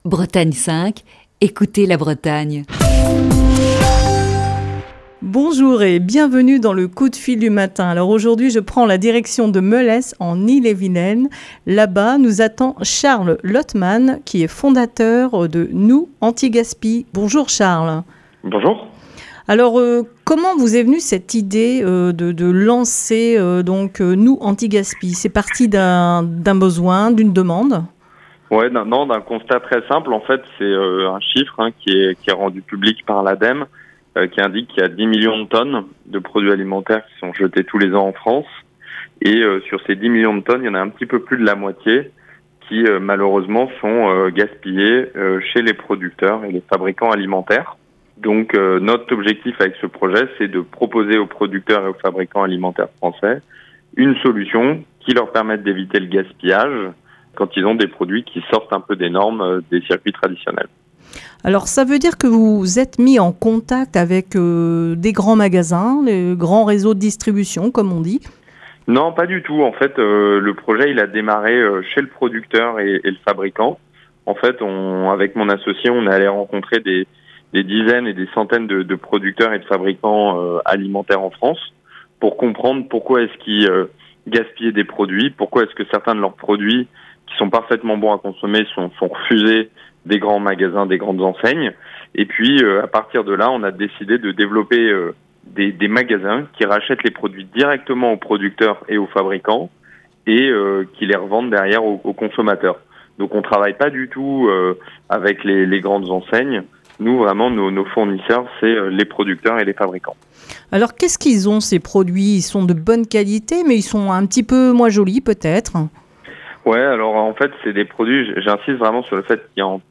passe un coup de fil